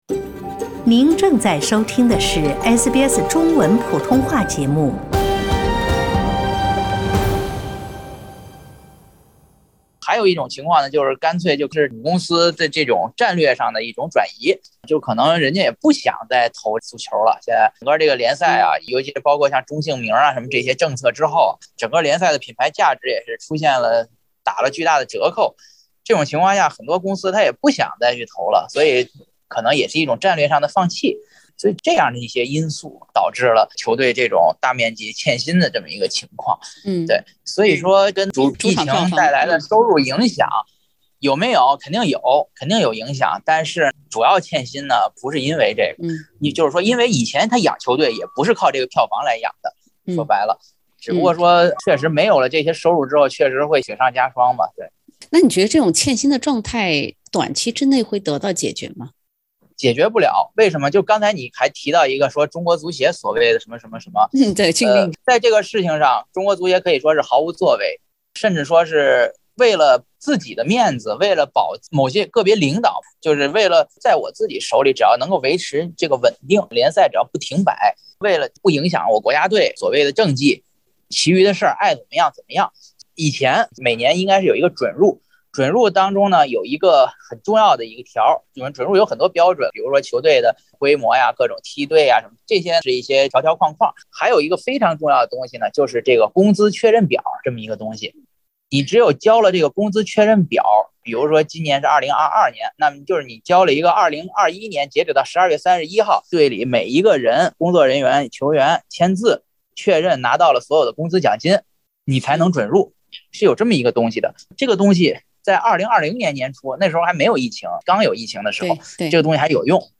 （點擊封面圖片，收聽完整寀訪） 從2020年開始，中超俱樂部就開始出現欠薪，而中國足協也在噹年明確表態，解決不了這個問題就取消俱樂部注冊資格，直接無緣中超聯賽。